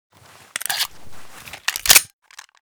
sr1m_reload_full.ogg